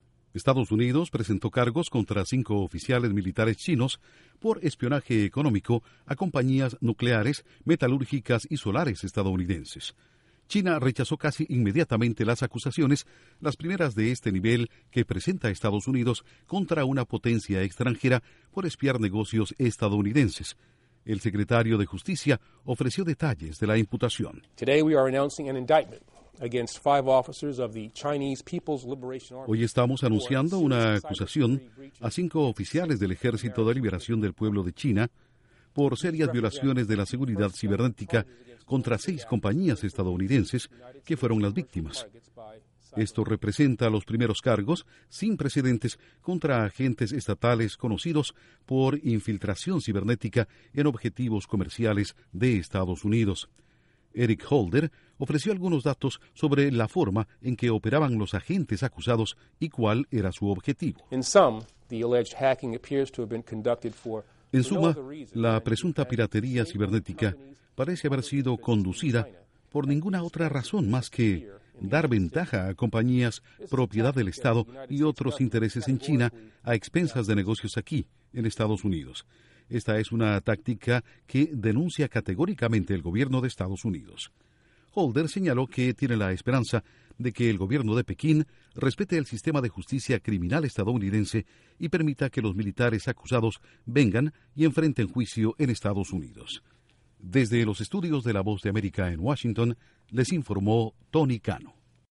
El Secretario de Justicia de Estados Unidos espera que China permita a los militares acusados de espionaje cibernético vengan a territorio estadounidense para ser sometidos a juicio. Informa desde los estudios de la Voz de América en Washington